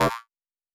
Buzz Error (14).wav